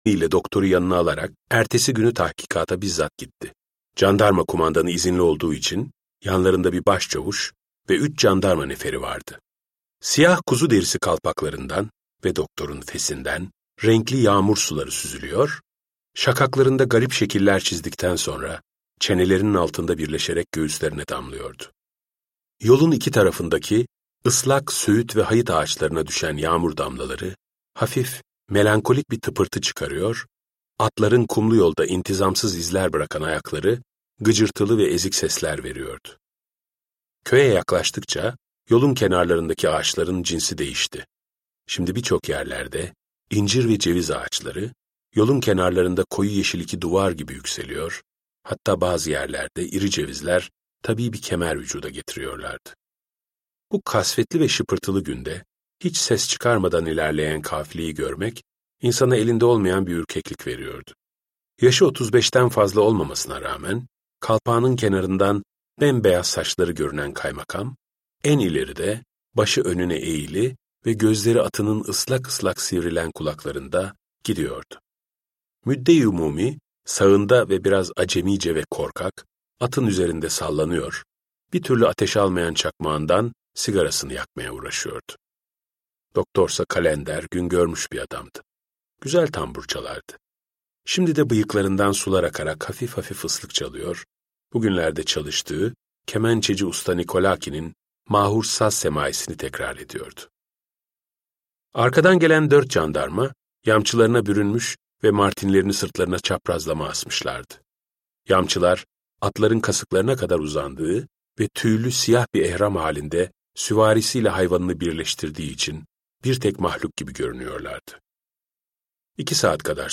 Kuyucaklı Yusuf - Seslenen Kitap
Kuyucaklı Yusuf’u Ezel Akay’ın seslendirmesiyle dinleyebilirsiniz.